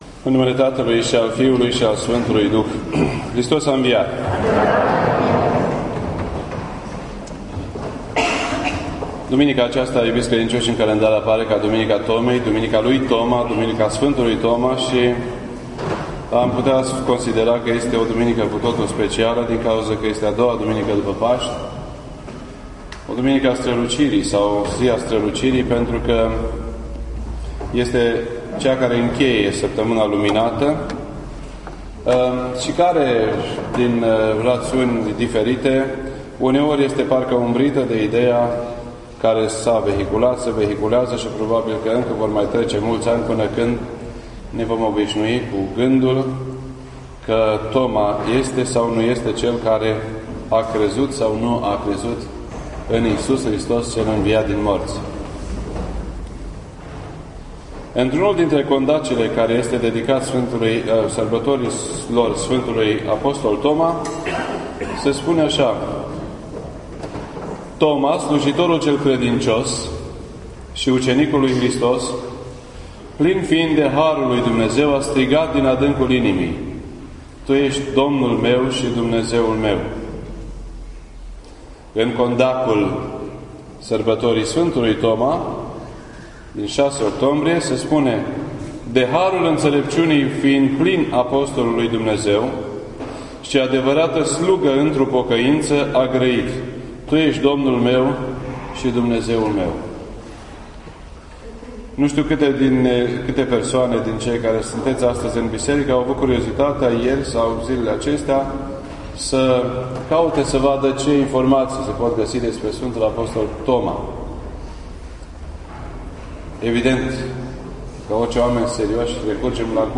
This entry was posted on Sunday, May 12th, 2013 at 8:43 PM and is filed under Predici ortodoxe in format audio.